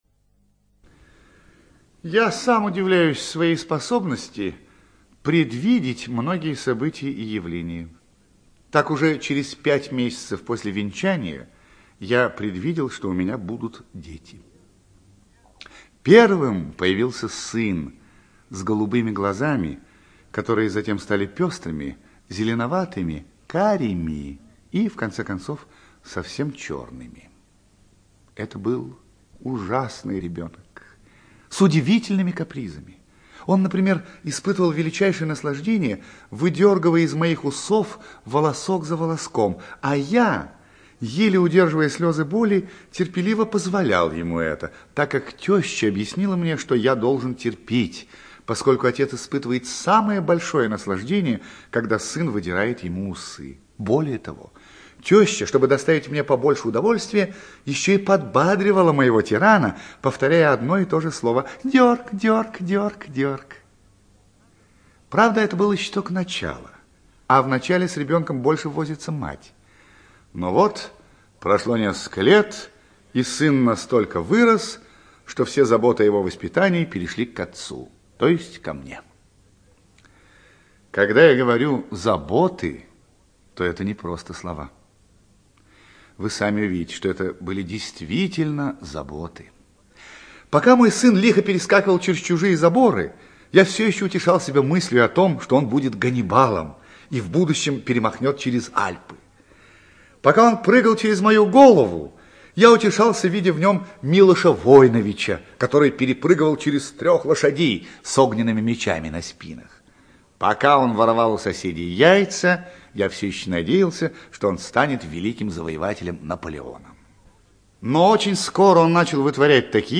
ЧитаетПлятт Р.